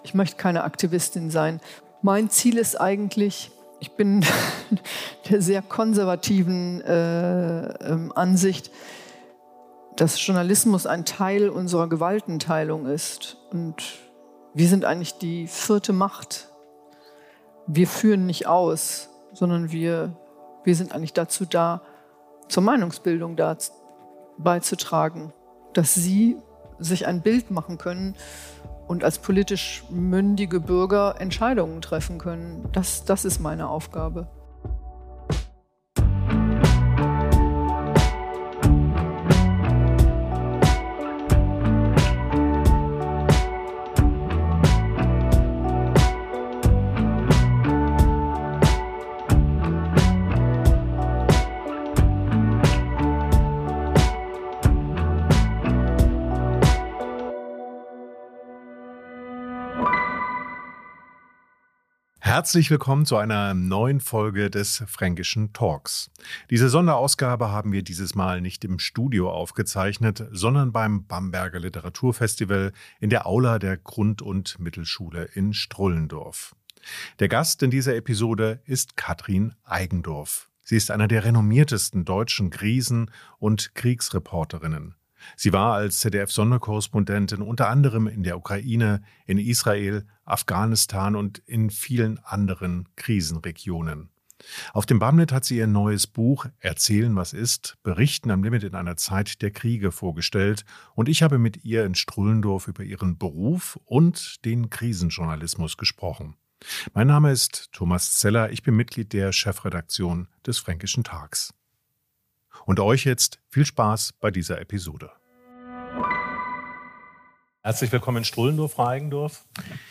Im Fränkischen Talk Spezial spricht die erfahrene Krisen- und Kriegsreporterin unter anderem über ihre Zeit in Russland, die Gefahren von Desinformation und ihre Verpflichtung zur Wahrheit.